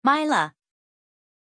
Pronunția numelui Mylah
pronunciation-mylah-zh.mp3